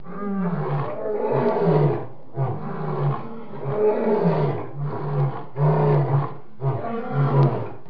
دانلود صدای حیوانات جنگلی 99 از ساعد نیوز با لینک مستقیم و کیفیت بالا
جلوه های صوتی